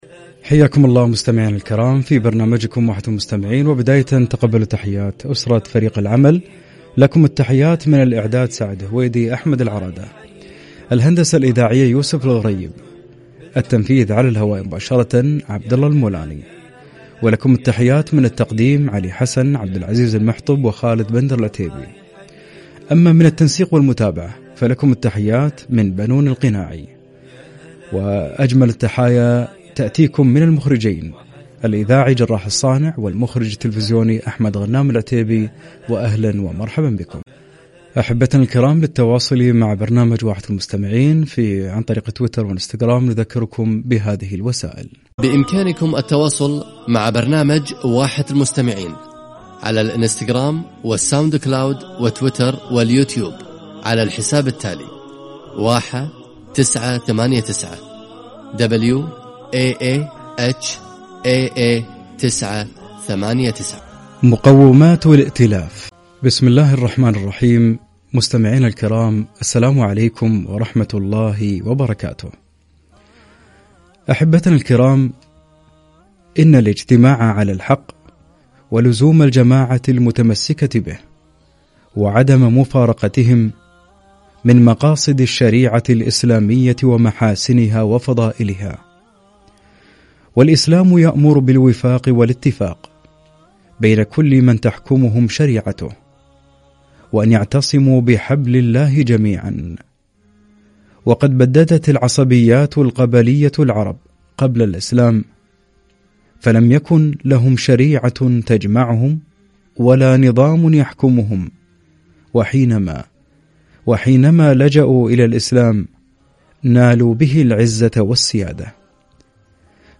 الائتلاف ونبذ الفرقة - لقاء عبر برنامج واحة المستمعين